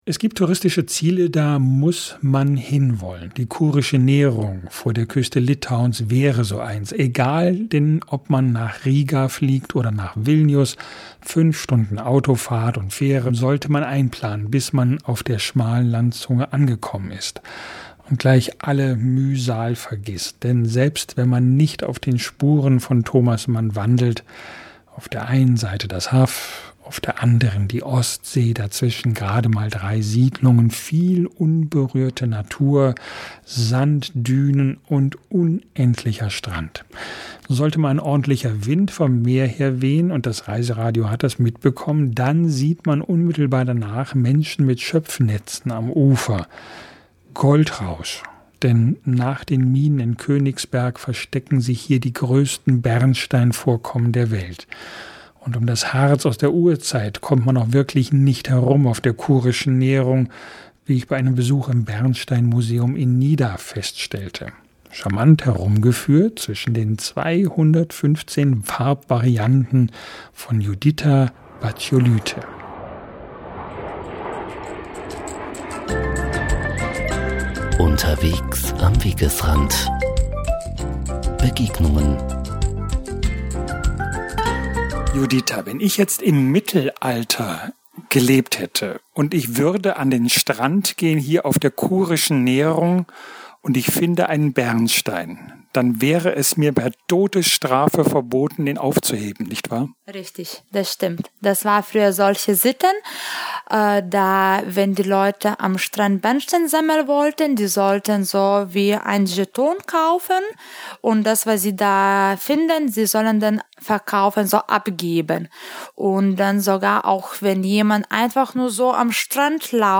Was Sie schon immer über das Gold des Nordens wissen wollen, im Reiseradio klärt eine litauische Expertin auf.